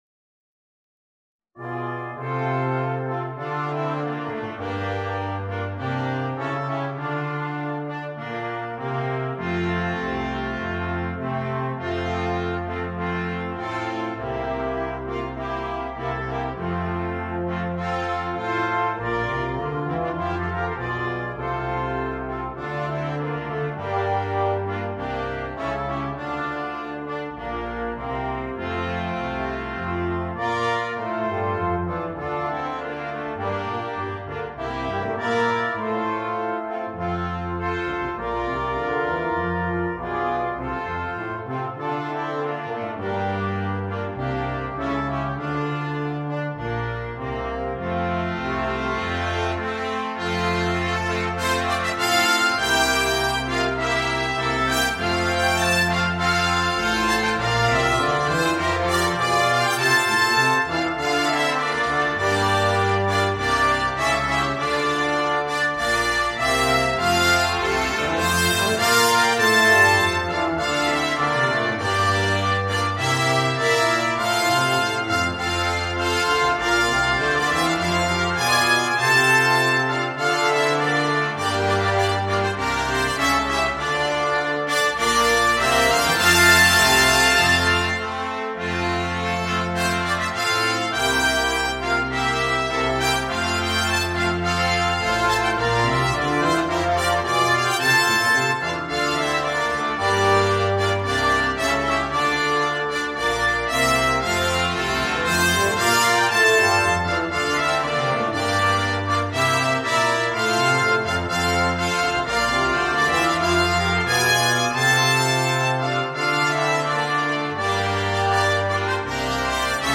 Trombone part in BC and TC.
The MP3 was recorded with NotePerformer 3.
Folk and World